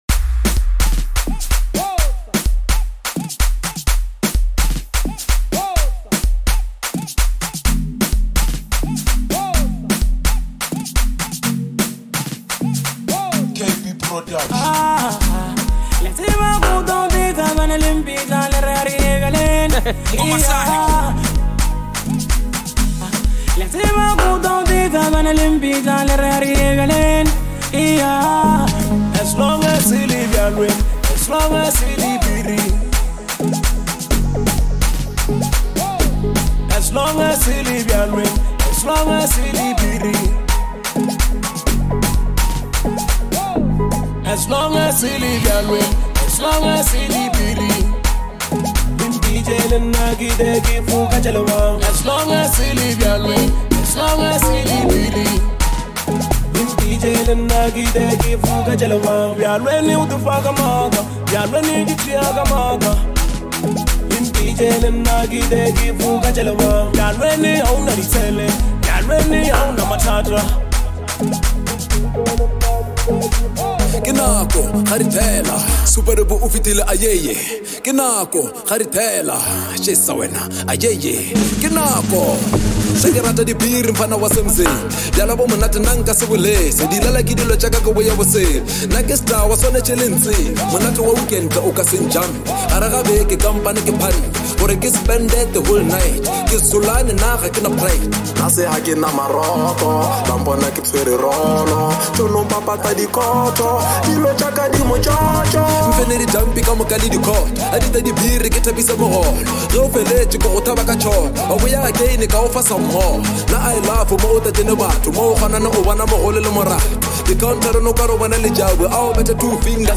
is a vibrant and energetic track